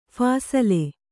♪ phasale